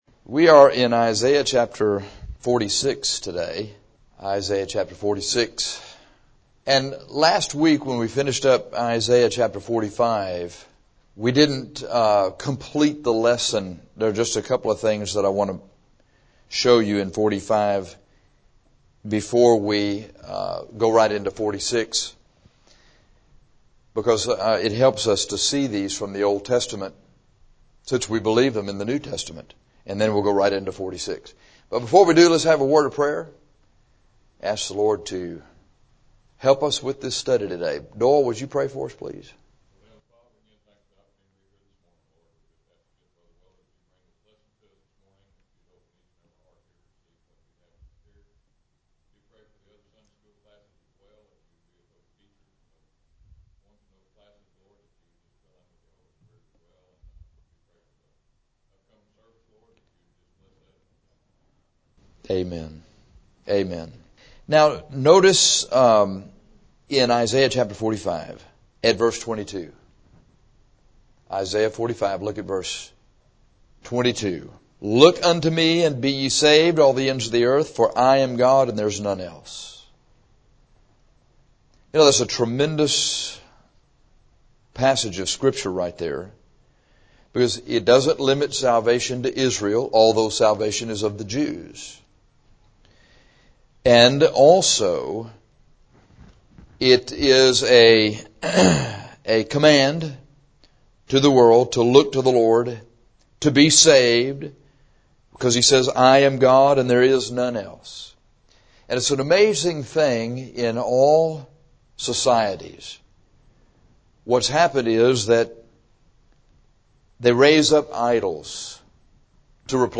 This lesson is a running commentary, verse by verse, through Is 46:1-13.